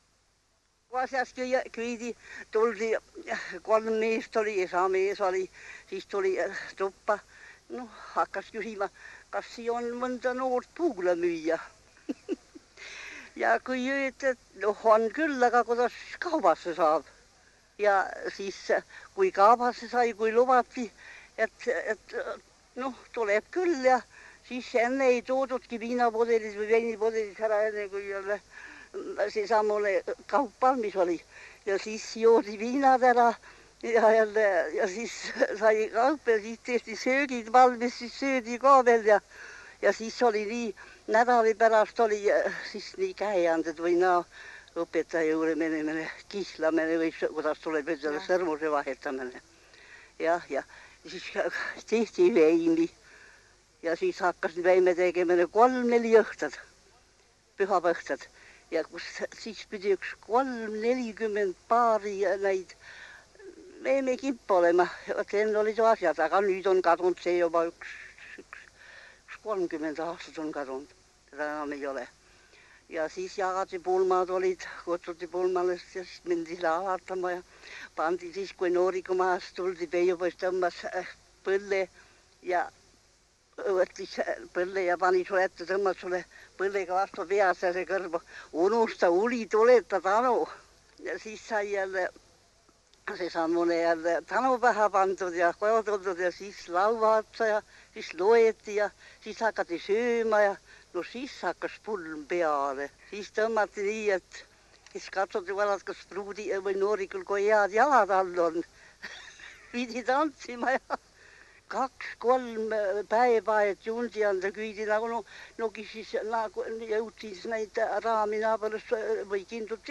MurdekiikerLäänemurreLMihkli